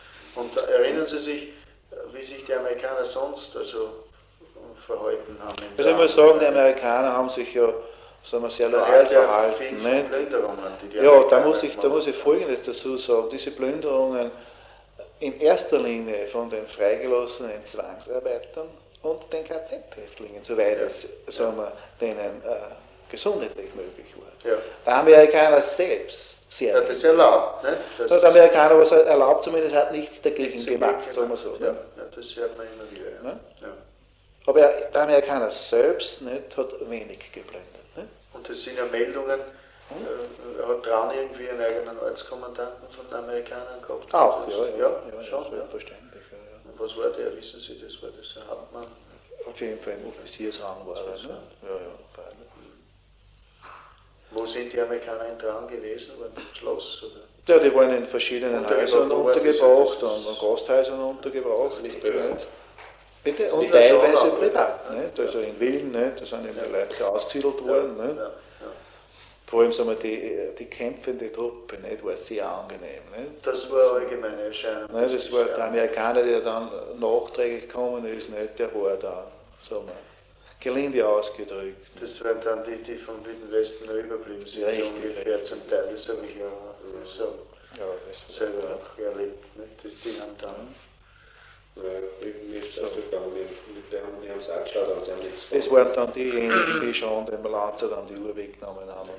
Dokumente aus dem Archiv